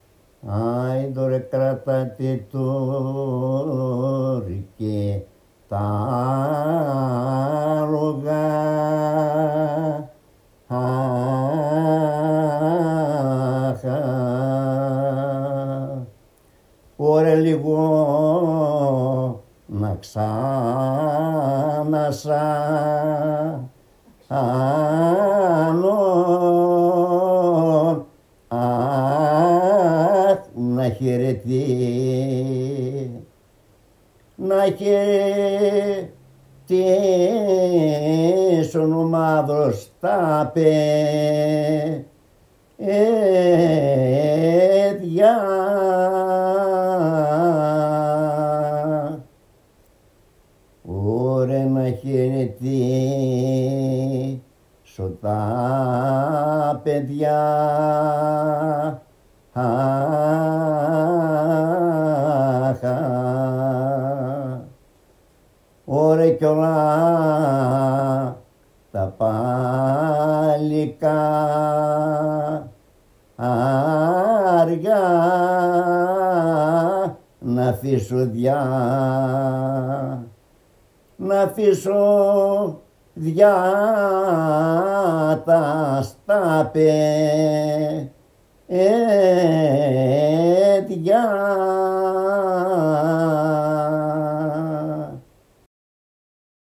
Επιτόπια έρευνα σε κοινότητες των Σαρακατσάνων της περιοχής Σερρών για τον εντοπισμό του τραγουδιστικού τους ρεπερτορίου